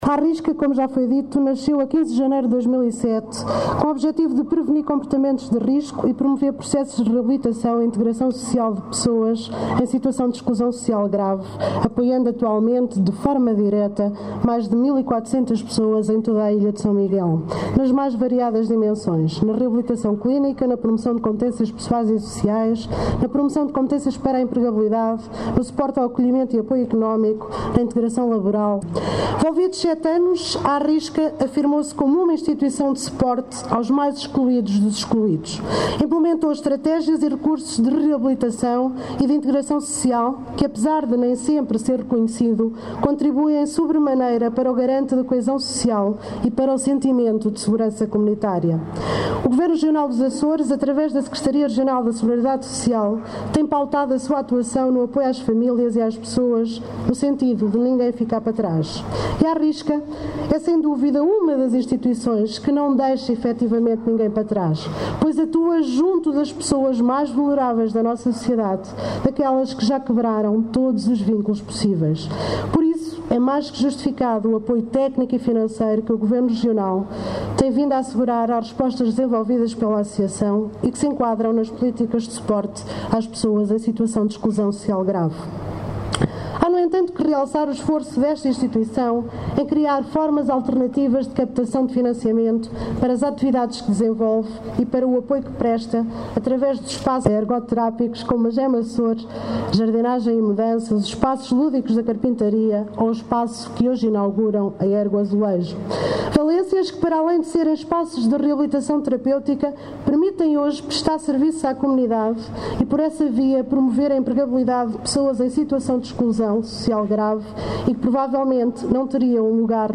A Diretora Regional da Solidariedade Social afirmou hoje, na inauguração deste espaço, que a iniciativa se insere no âmbito da política do Governo dos Açores que visa “dotar as instituições sociais de recursos e estratégias facilitadoras da melhoria da sua eficiência de gestão e diminuição dos custos de exploração”.